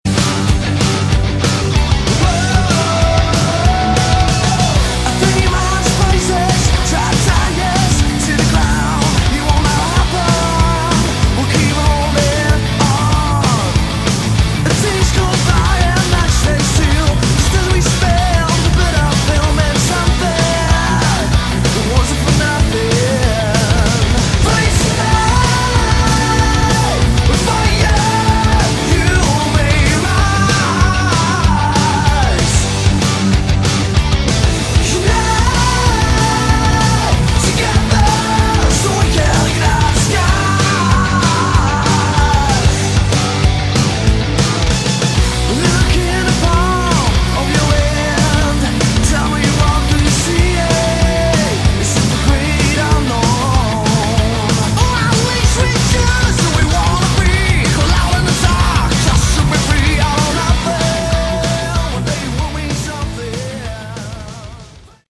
Category: Hard Rock
I like the more dangerous, youth gone wild sleazy edge.